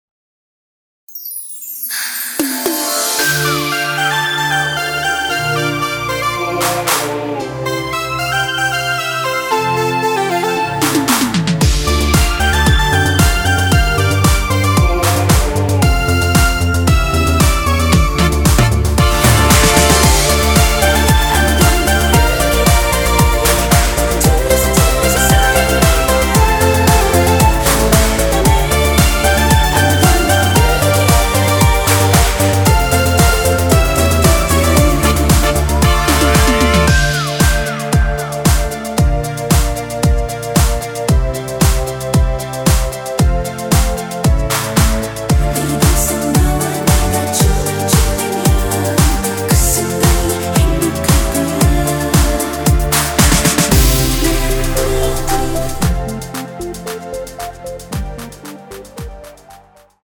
원키 코러스 포함된 MR입니다.(미리듣기 확인)
Dm
앞부분30초, 뒷부분30초씩 편집해서 올려 드리고 있습니다.